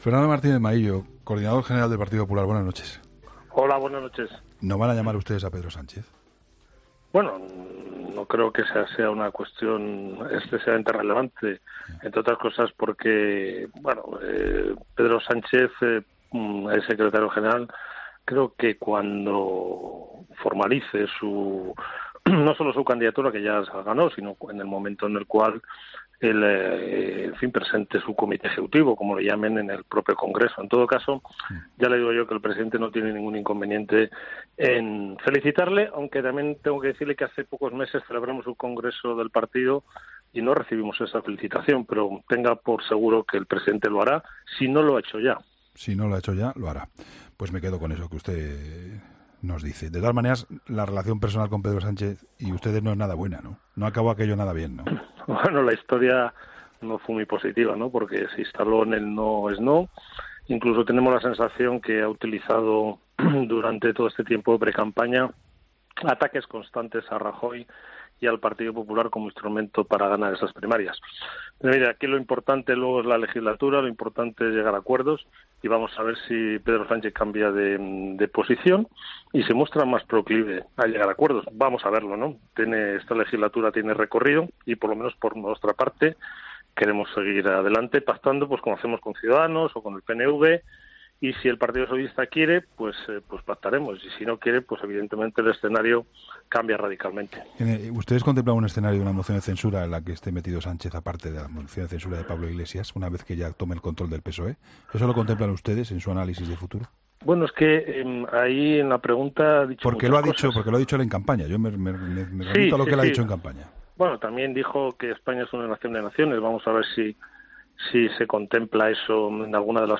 Entrevista a Fernando Martínez-Maillo